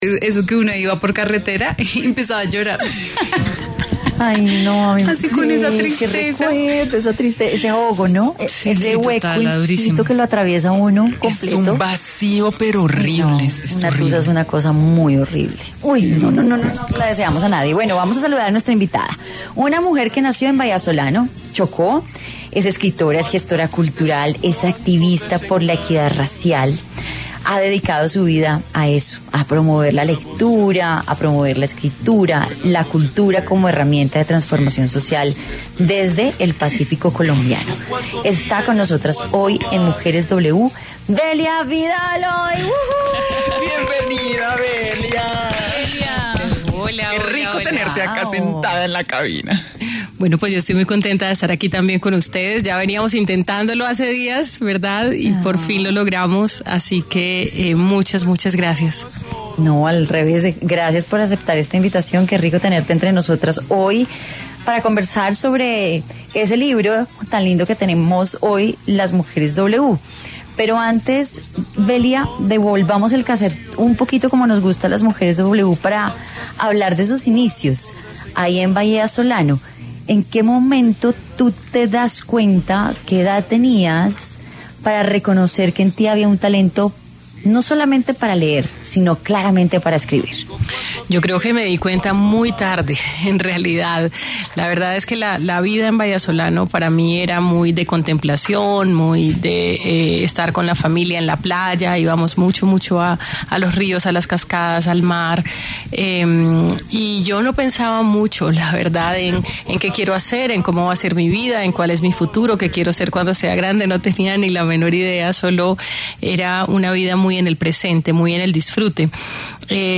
en entrevista con W Radio nos contó como ha usado la lectura para lograr una transformación social en el Pacífico colombiano.